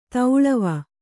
♪ tauḷava